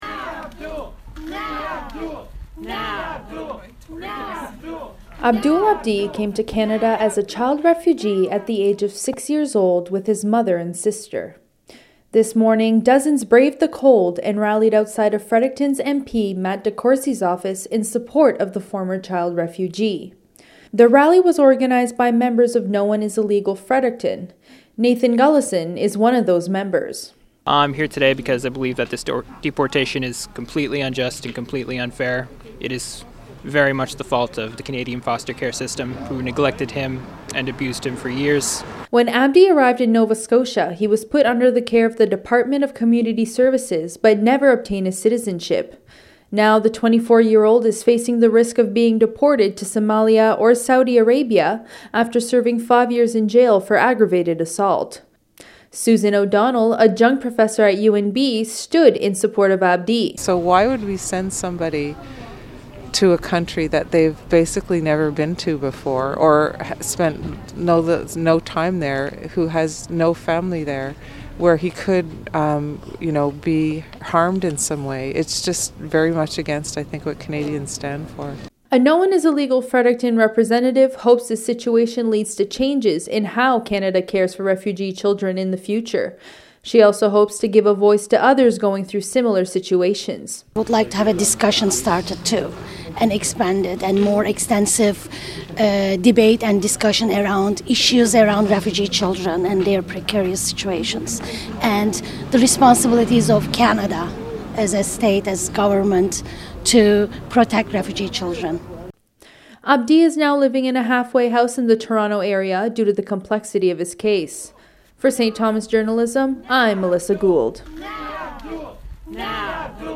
Members of the Fredericton community outside the office of MP Matt DeCourcey on Jan. 15, 2018